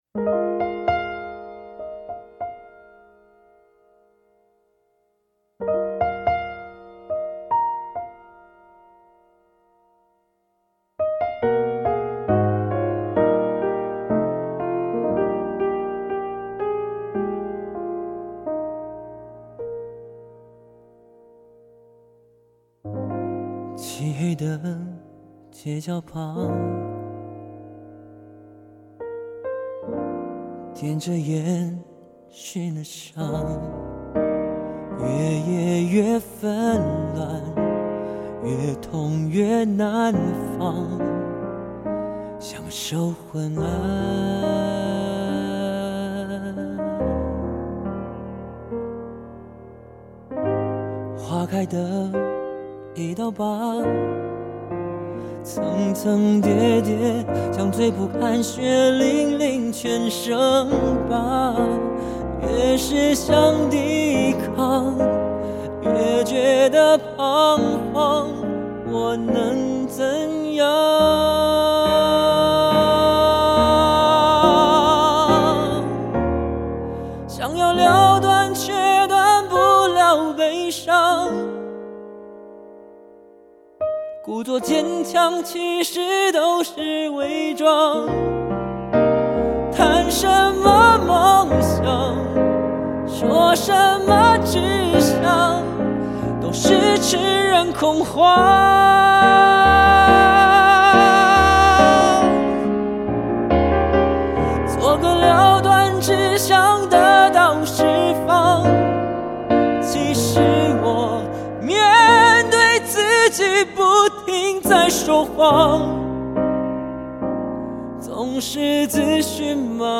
纯粹钢琴与弦乐配置的编曲巧思